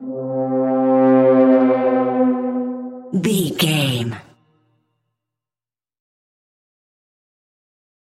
In-crescendo
Thriller
Aeolian/Minor
scary
tension
ominous
dark
suspense
haunting
eerie
piano
percussion
synths
atmospheres